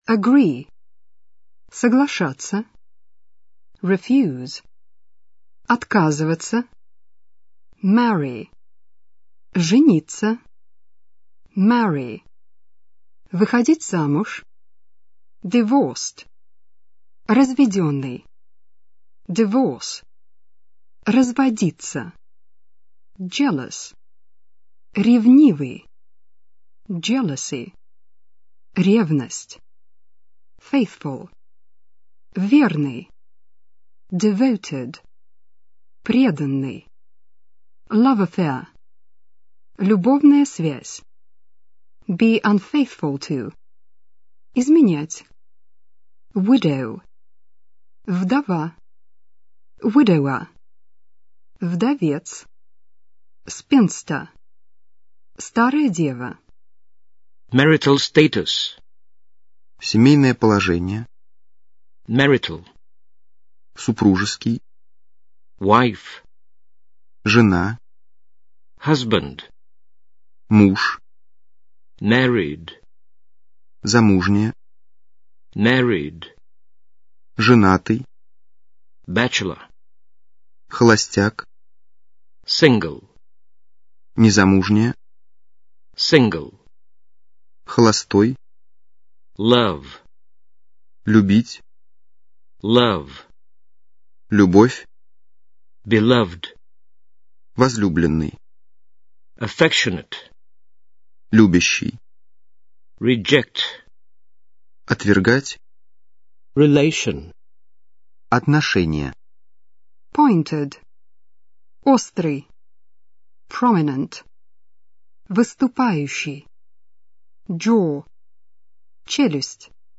Здесь выложен аудио словарь примерно на 500 английских слов с их переводом на русский язык.